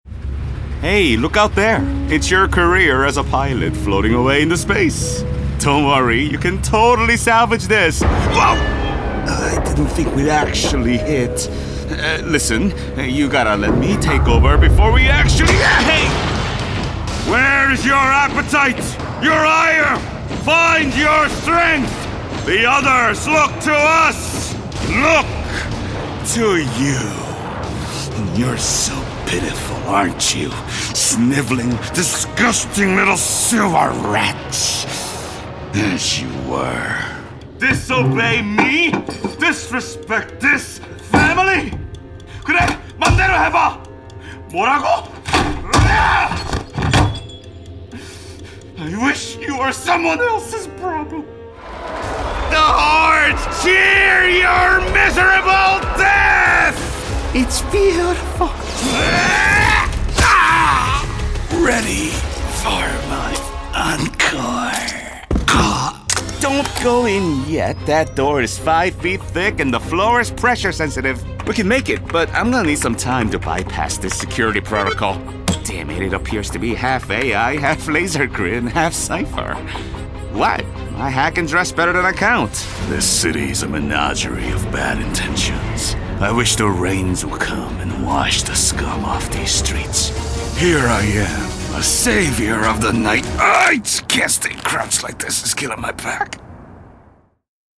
A full-time voice actor who is native-level bilingual in English and Korean, serving my clients from a broadcast-quality, Source-Connect Standard equipped home studio in Los Angeles.
Mature Adult, Adult
Location: Los Angeles, CA, USA Languages: english 123 korean Accents: international english Voice Filters: VOICEOVER GENRE commercial commercial gaming promos HOME STUDIO source connect standard